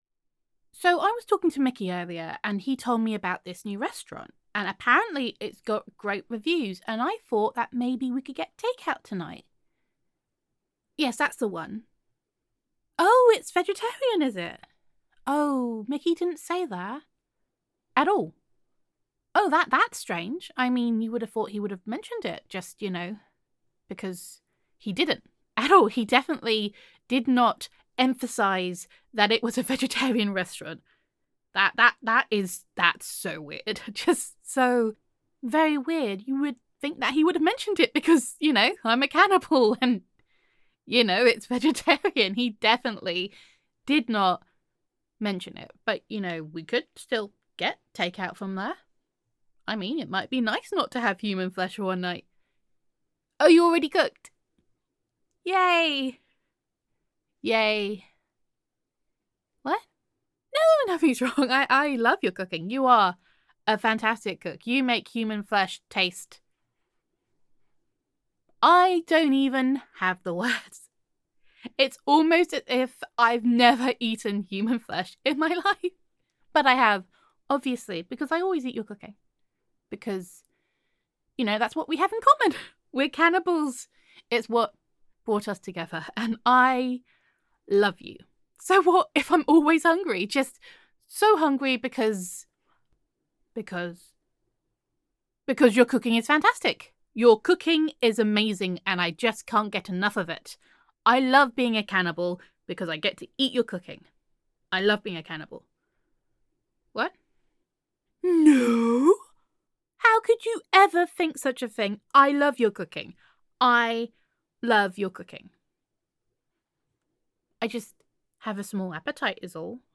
[F4A] Honey the Not-So-Cannibal [Let's Get Takeout][Nandos][Surprisingly Sweet][Gender Neutral][You Can Only Pretend to Be a Cannibal for So Long]